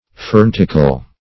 Search Result for " fernticle" : The Collaborative International Dictionary of English v.0.48: Fernticle \Fern"ti*cle\, n. A freckle on the skin, resembling the seed of fern.
fernticle.mp3